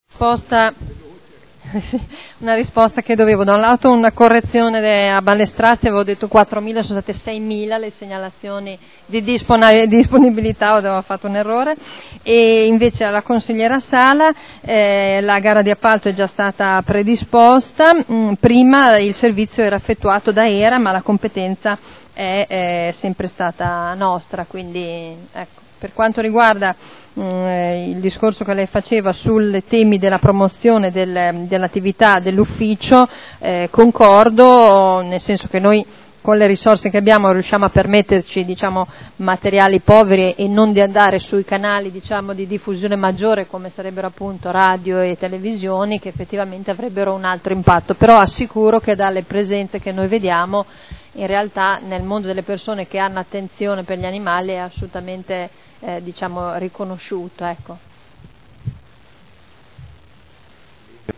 Seduta del 22/12/2011.